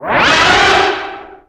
caulthit2.ogg